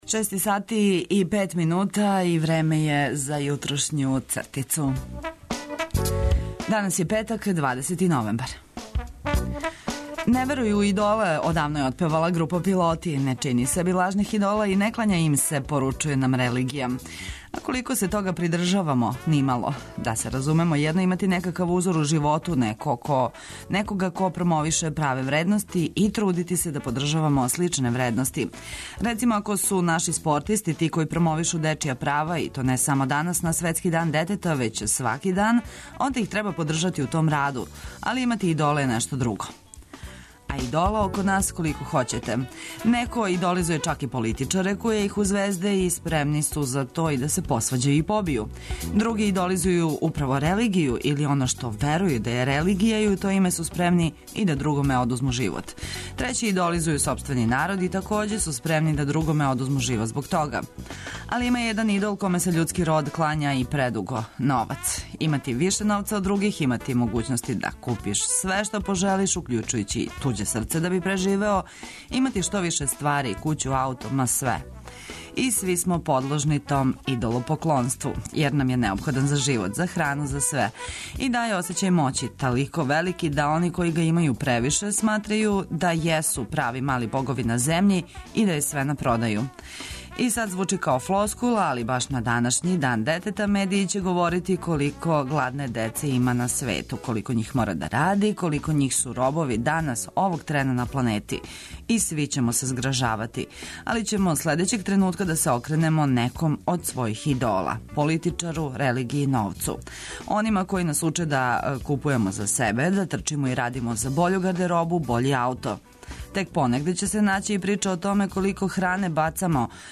Водитељ